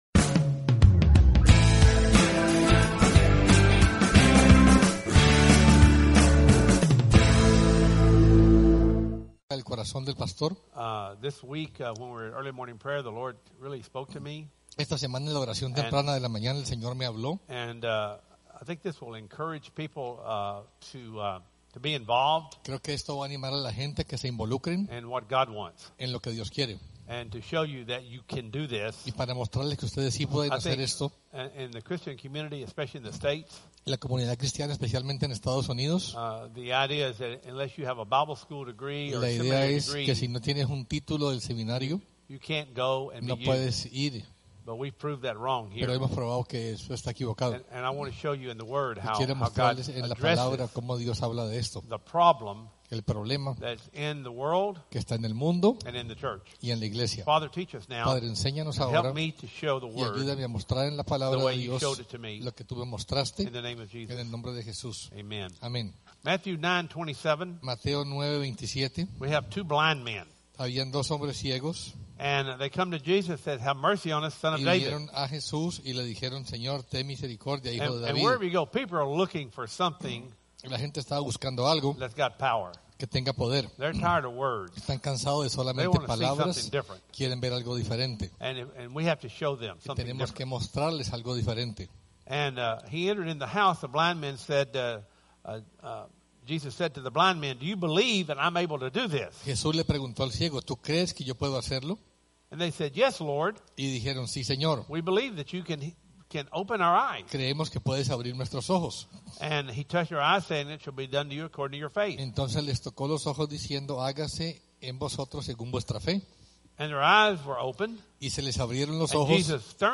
Sunday 2016 Services Service Type: Sunday Service « Eight Things Jesus Said on the Cross Leviathan